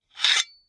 描述：剑画来自我的大学项目。 都是以96kHz 24位录制的
声道立体声